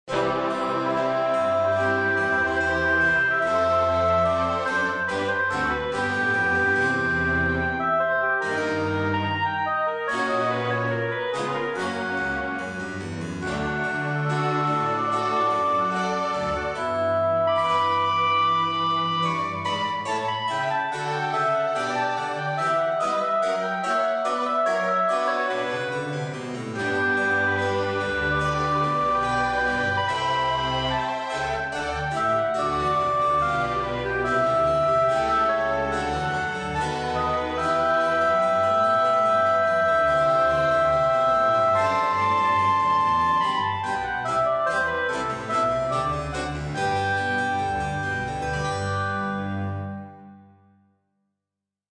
~ САУНДТРЕК ~